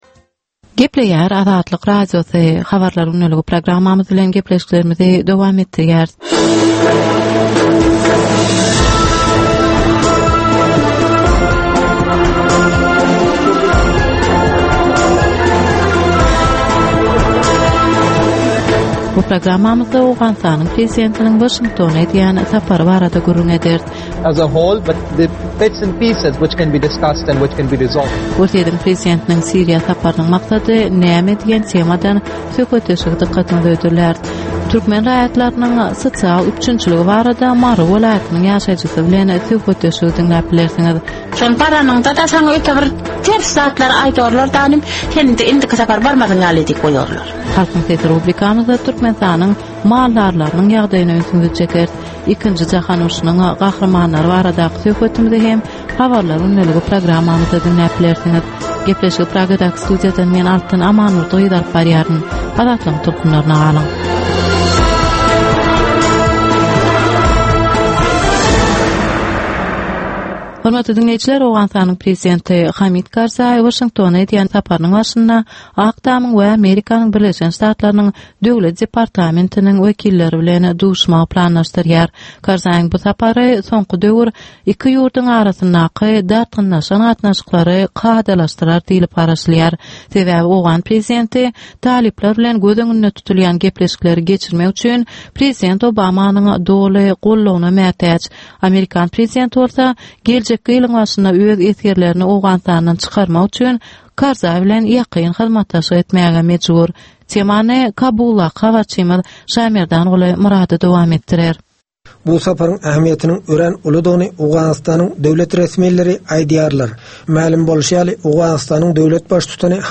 Türkmenistandaky we halkara arenasyndaky soňky möhüm wakalar we meseleler barada ýörite informasion-analitiki programma. Bu programmada soňky möhüm wakalar we meseleler barada ginişleýin maglumatlar, analizler, synlar, makalalar, söhbetdeşlikler, reportažlar, kommentariýalar we diskussiýalar berilýär.